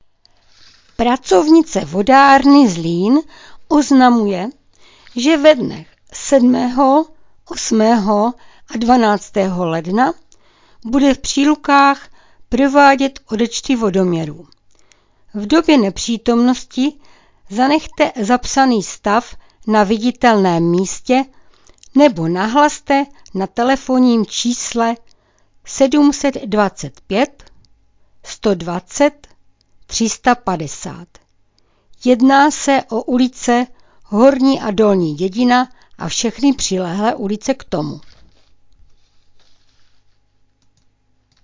Hlášení místního rozhlasu
Samotné hlášení provádí pracovníci kanceláří místních částí ze svých pracovišť.
Hlášení ze dne 6.1.2026